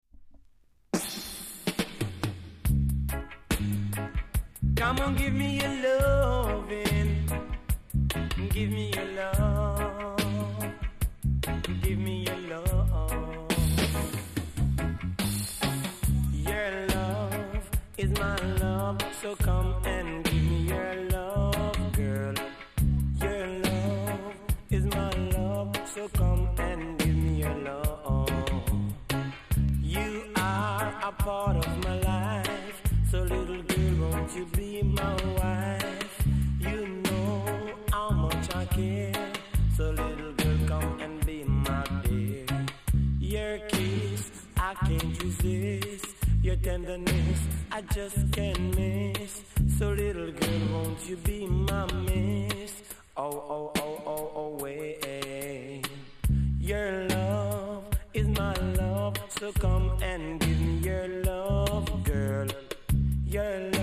HEAVY 80's VOCAL!!